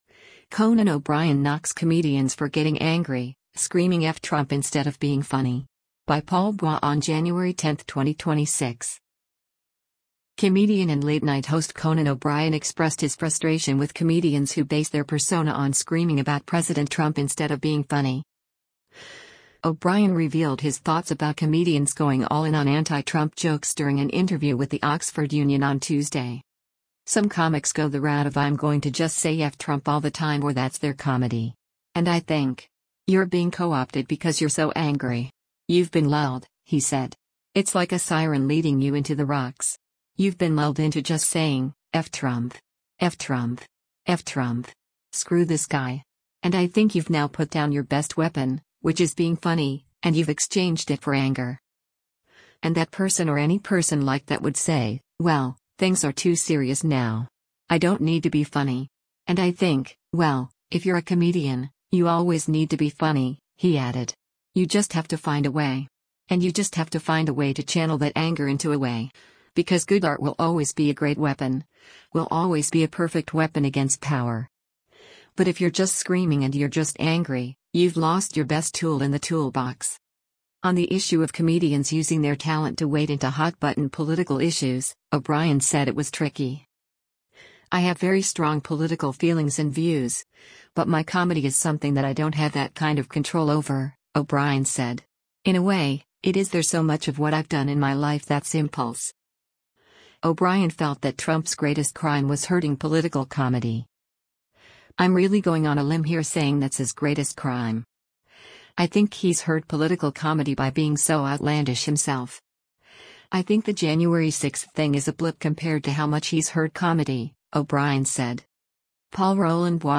O’Brien revealed his thoughts about comedians going all in on anti-Trump jokes during an interview with the Oxford Union on Tuesday.